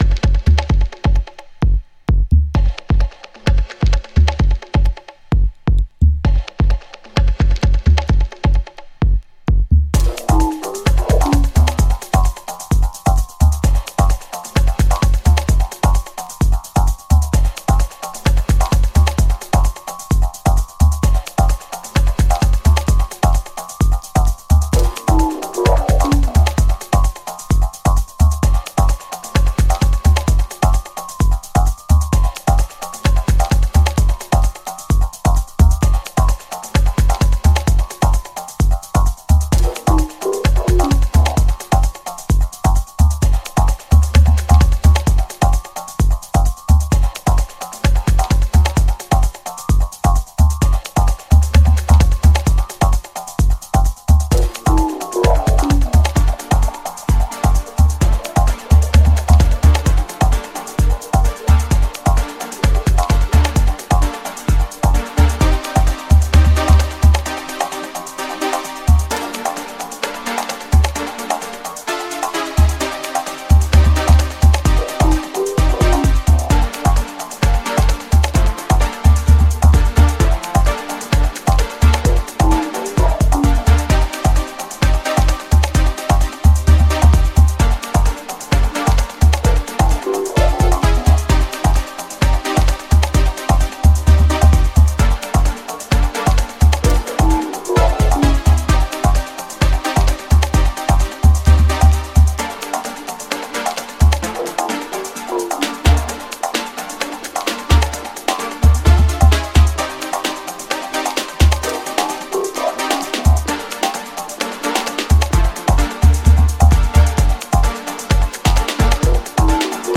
House Dub Bass